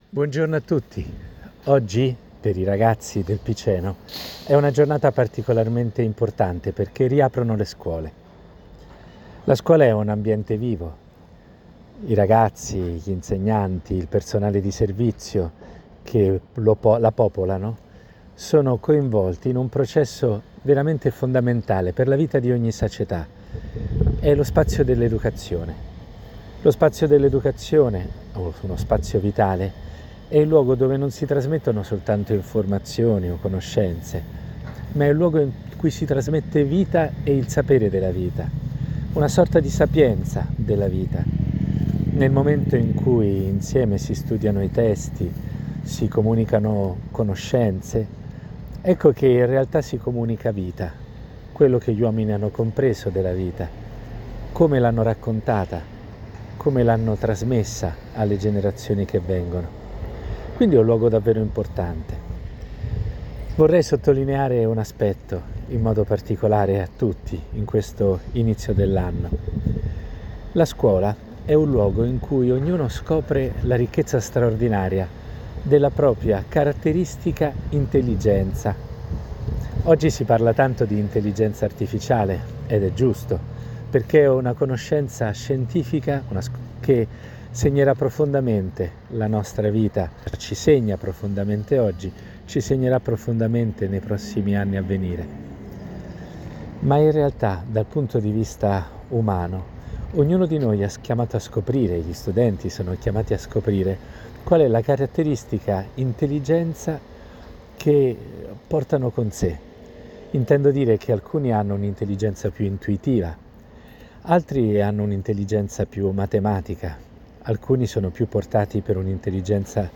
Di seguito, il podcast del Vescovo Palmieri: